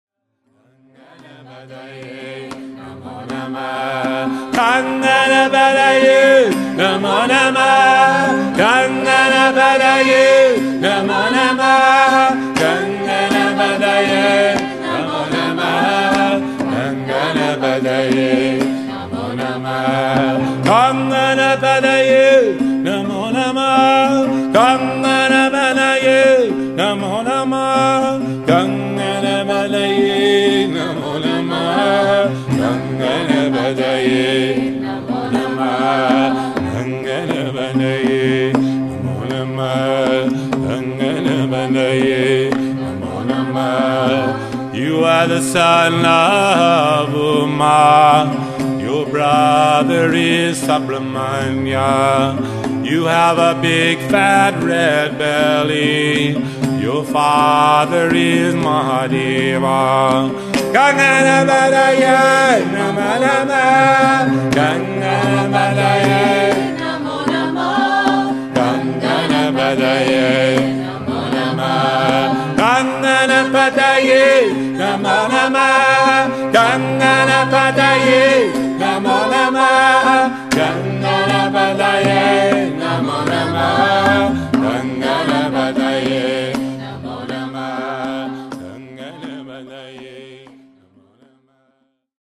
Live Kirtan Chanting CD